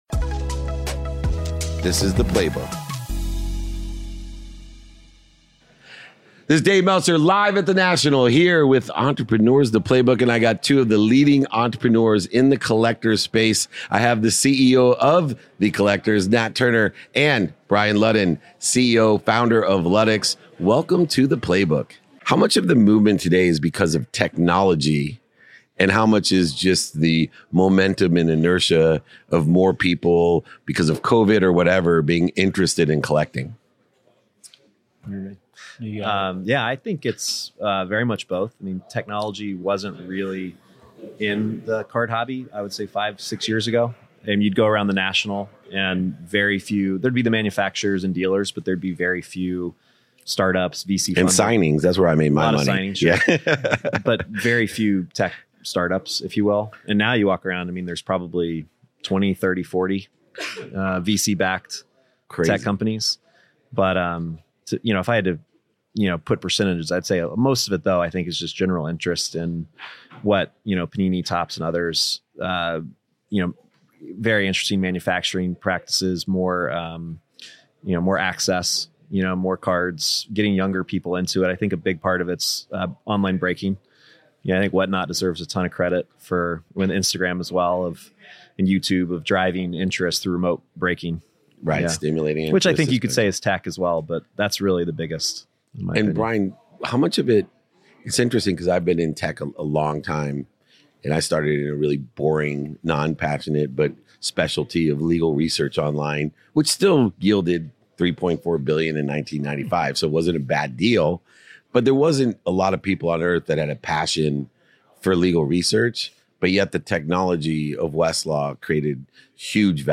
Today's episode is from a conversation I had at The National Trading Convention